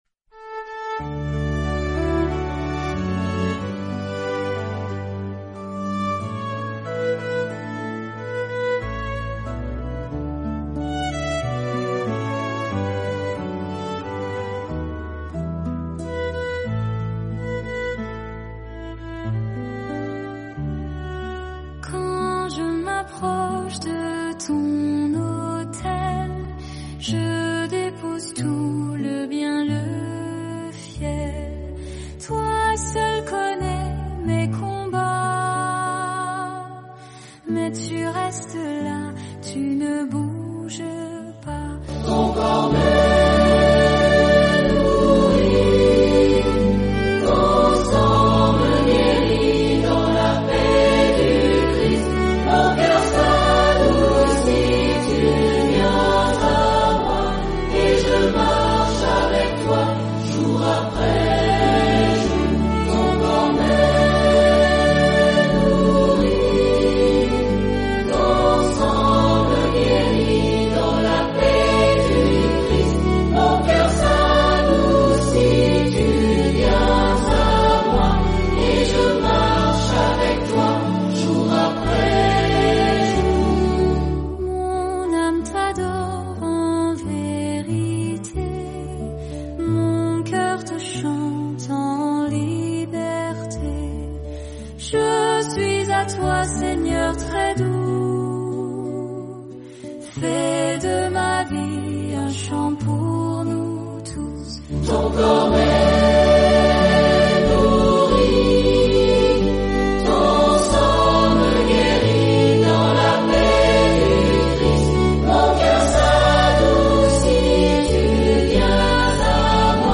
Ton corps me nourrit, Chant d'usage à la communion